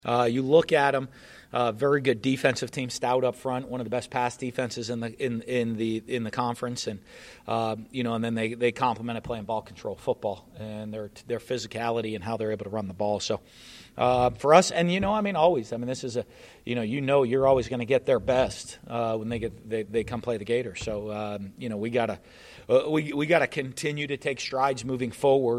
Florida football head coach Dan Mullen addressed the media on Monday afternoon in his weekly press conference.